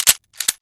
gg_reload.wav